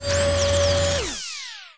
powerdown.wav